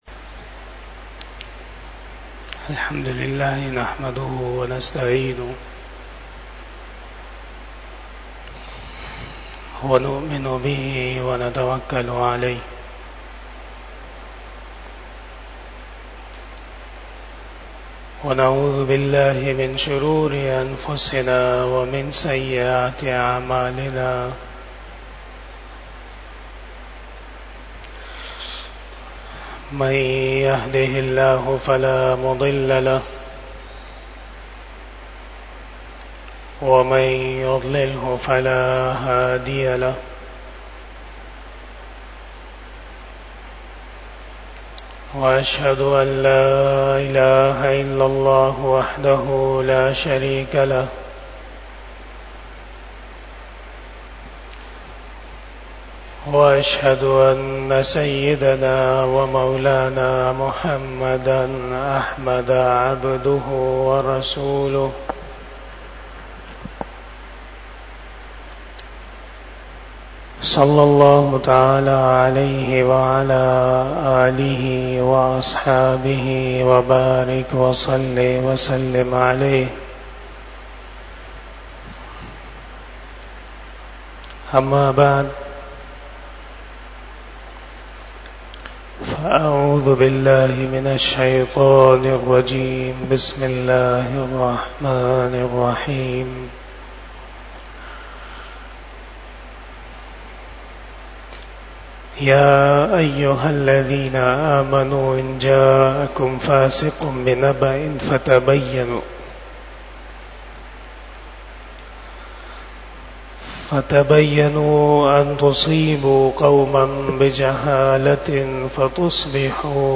26 Bayan E Jummah 28 June 2024 (21 ZilHajjah 1445 HJ)
Khitab-e-Jummah